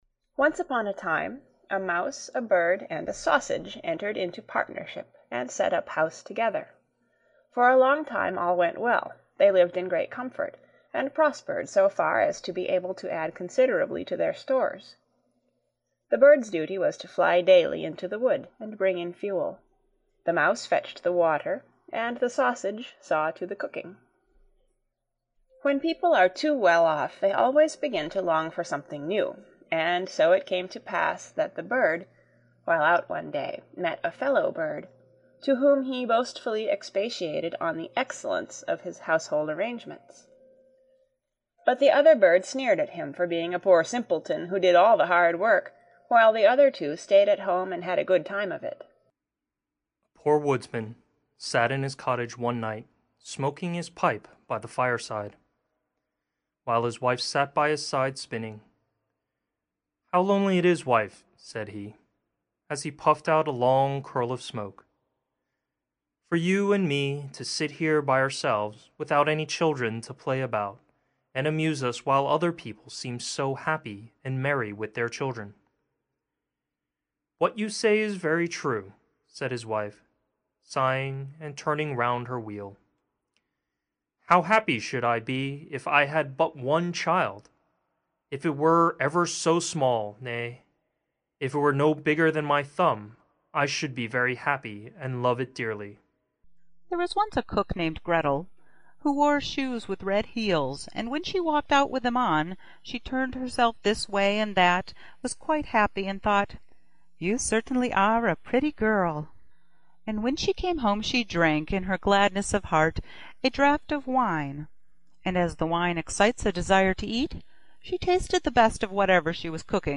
Grimms’ Fairy Tales By The Brothers Grimm | Original And Classic Audiobook